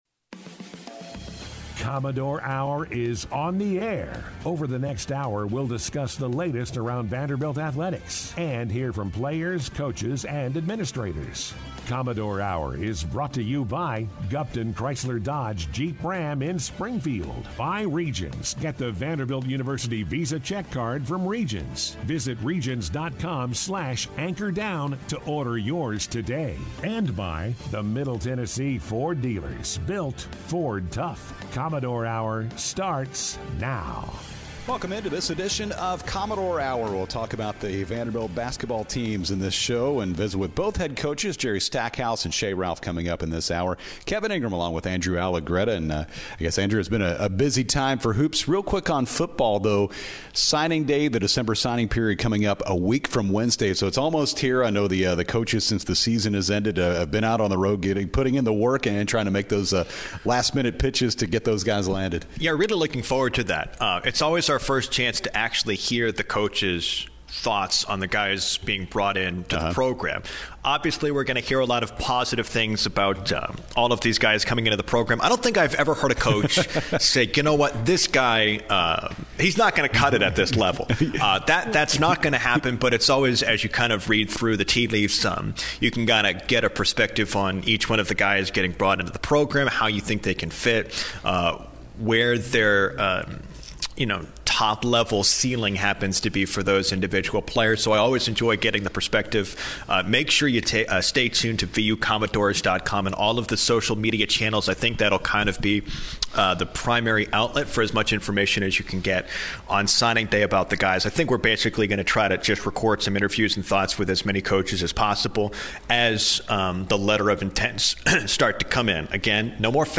Guests on this week's Commodore Hour, Mondays from 6-7 PM on ESPN 94.9: